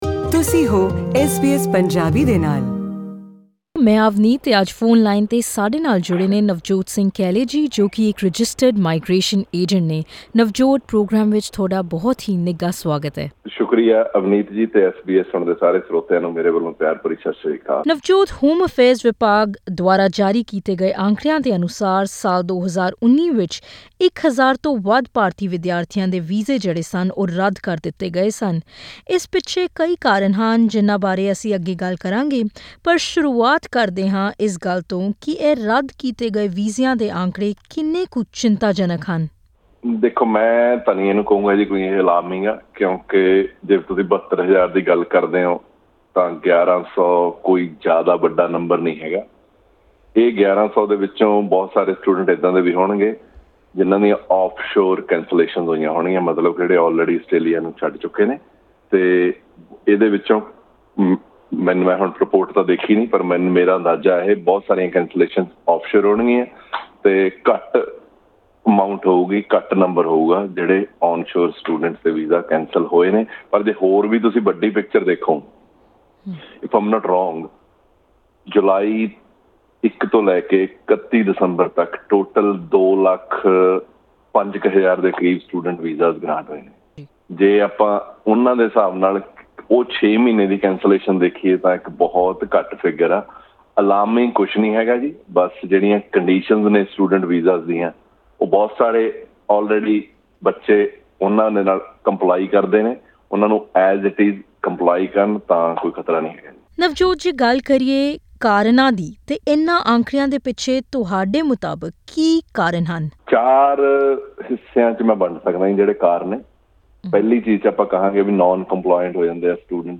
2019 ਵਿੱਚ ਹਜ਼ਾਰਾਂ ਹੀ ਅੰਤਰਰਾਸ਼ਟਰੀ ਵਿਦਿਆਰਥੀਆਂ ਨੇ ਕਈ ਕਾਰਨਾਂ ਕਰਕੇ ਆਪਣੇ ਵੀਜ਼ੇ ਗਵਾਏ, ਜਿਸ ਵਿੱਚ ਝੂਠੇ ਦਸਤਾਵੇਜ਼ ਮੁਹੱਈਆ ਕਰਾਉਣਾ ਅਤੇ ਵੀਜ਼ਾ ਸ਼ਰਤਾਂ ਦੀ ਪਾਲਣਾ ਨਾ ਕਰਨਾ ਸ਼ਾਮਿਲ ਹੈ। ਪੇਸ਼ ਹੈ ਇਸ ਬਾਰੇ ਇੱਕ ਵੀਜ਼ਾ ਮਾਹਿਰ ਨਾਲ਼ ਕੀਤੀ ਇਹ ਵਿਸ਼ੇਸ਼ ਗੱਲਬਾਤ।